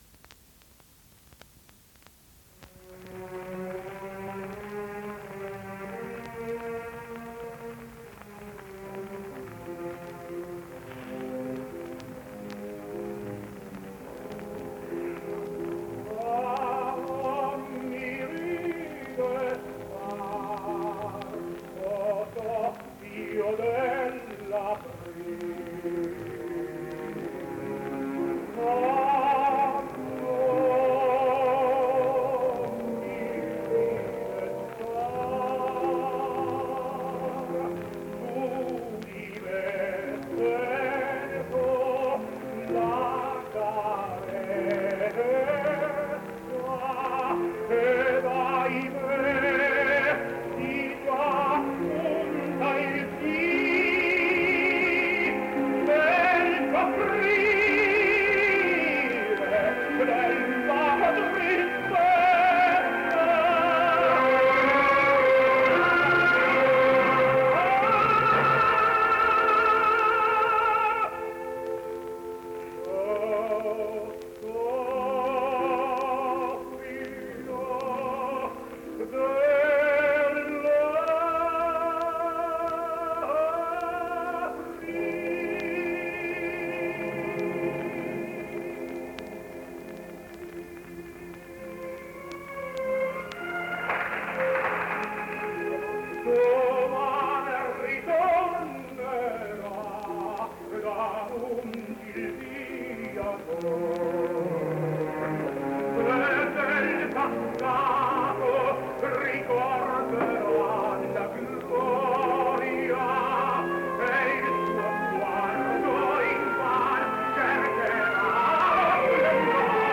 Tenore TITO SCHIPA “L’emozione”